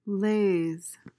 PRONUNCIATION: (layz) MEANING: verb tr.: To expose or process with a laser (e.g. to target an object or cut a material). verb intr.: To give off coherent light.